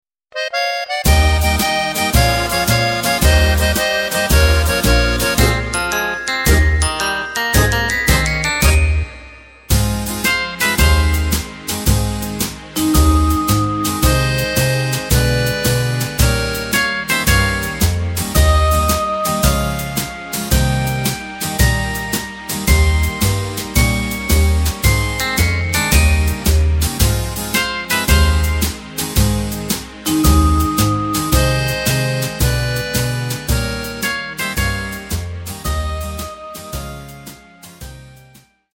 Takt:          2/4
Tempo:         111.00
Tonart:            Ab
Schlager Oldie aus dem Jahr 1957!
Playback mp3 mit Lyrics